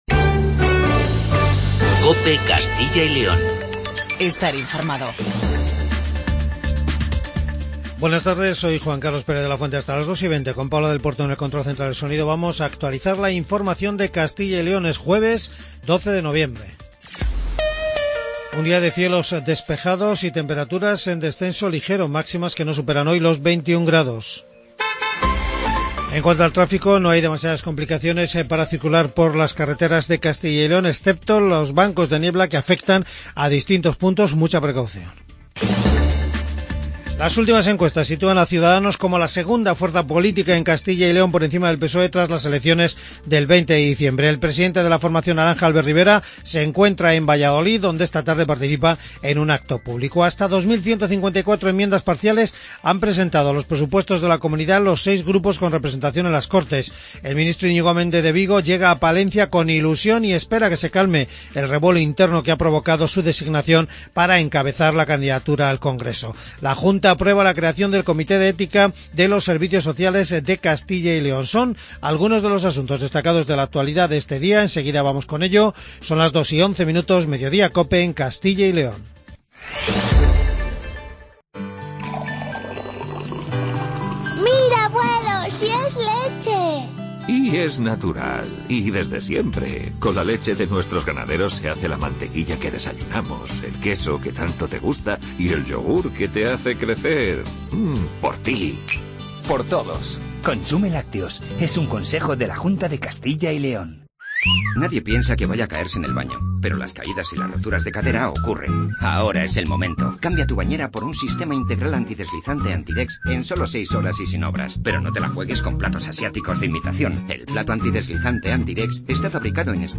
AUDIO: Informativo regional